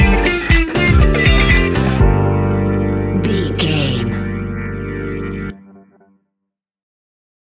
Aeolian/Minor
energetic
groovy
drums
bass guitar
electric guitar
electric piano
synths
upbeat
drum machines